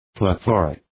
Προφορά
{ple’ɵɔ:rık}